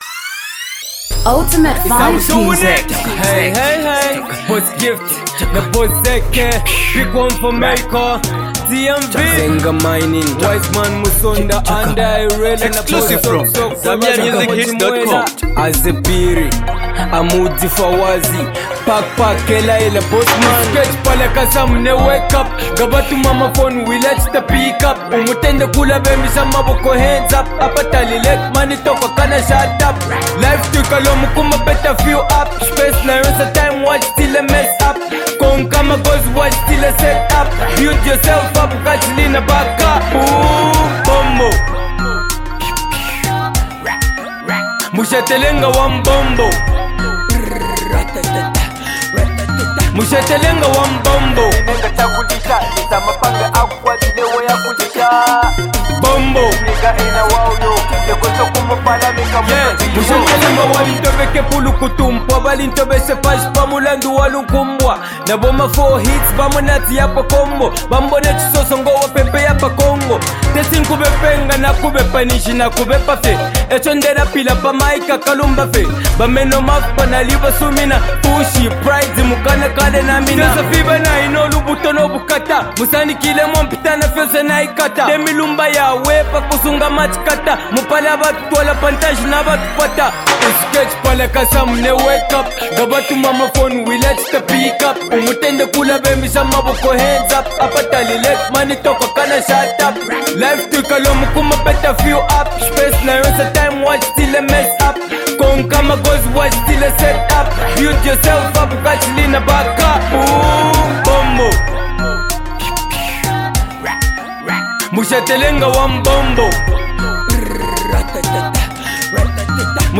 Danceable melody